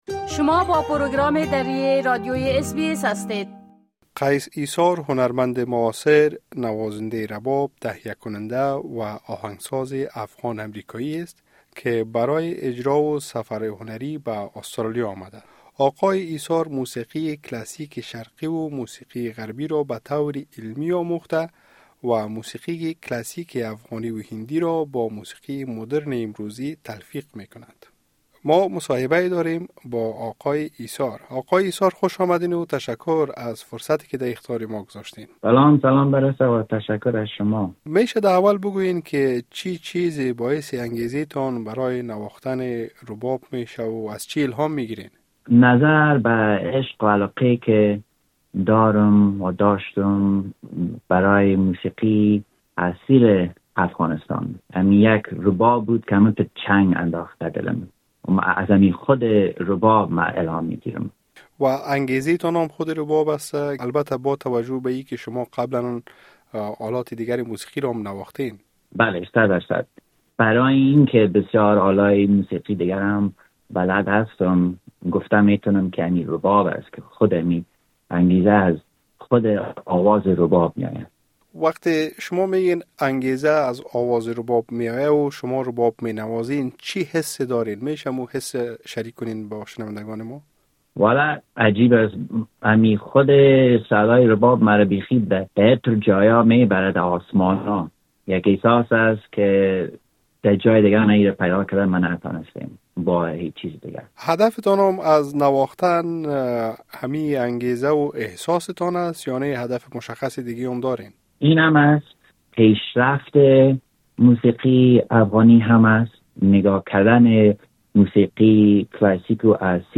آوای رباب؛ گفتگو